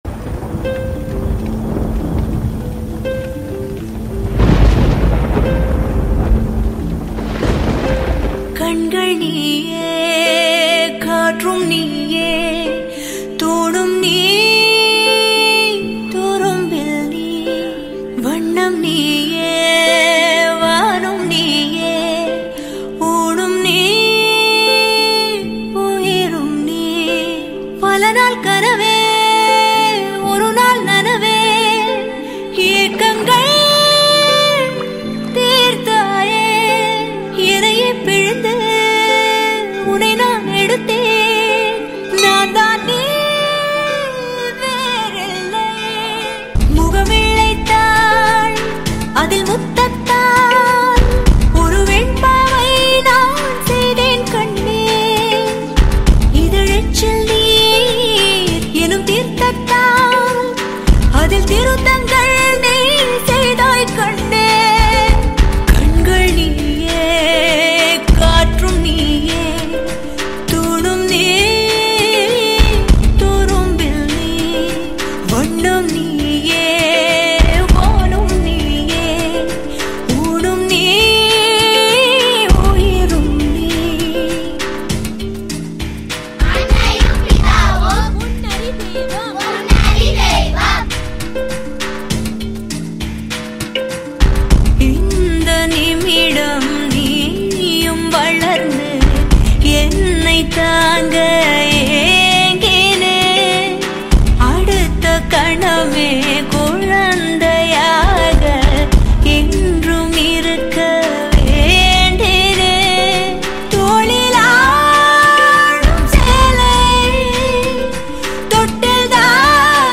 Releted Files Of Tamil Gana